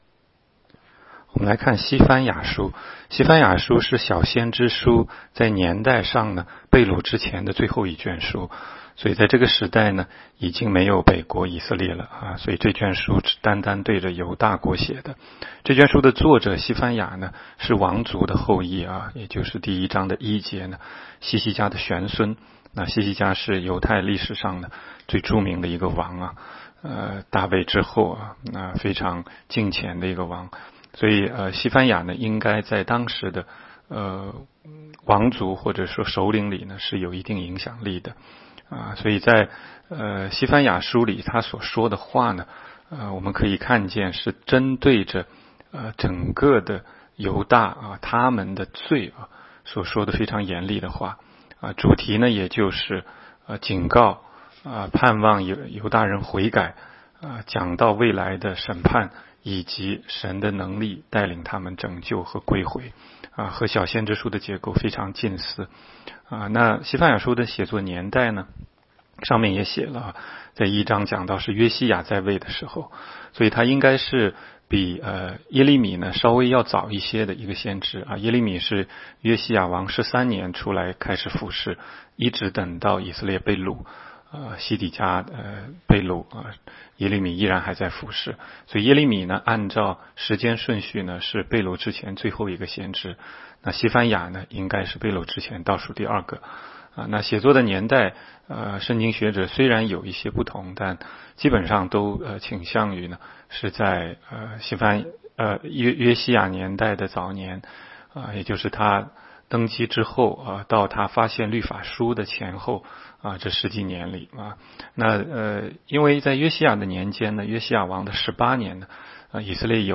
16街讲道录音 - 每日读经 -《西番雅书》1章